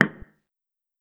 check-off.wav